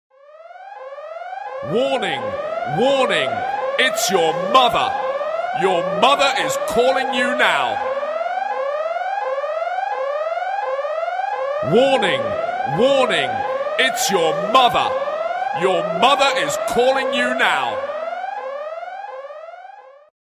• Качество: 192, Stereo
смешные